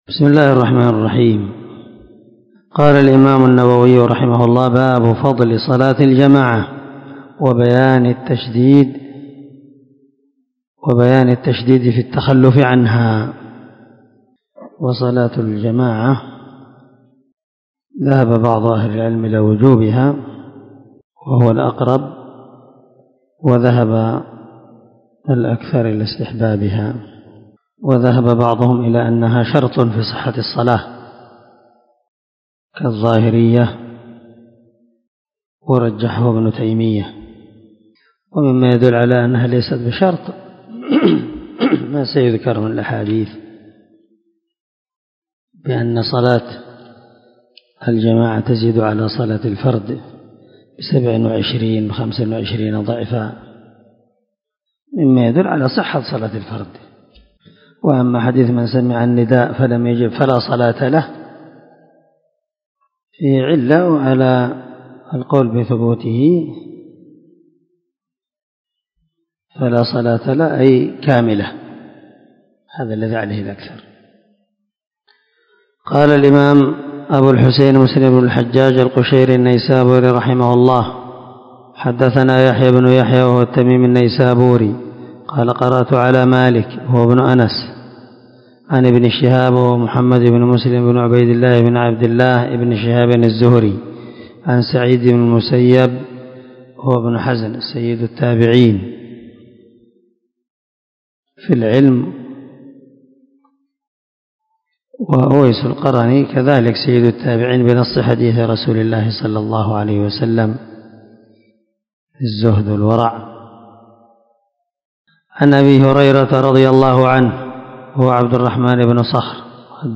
410الدرس 82من شرح كتاب المساجد ومواضع الصلاة حديث رقم ( 649 - 650 ) من صحيح مسلم
دار الحديث- المَحاوِلة- الصبيحة.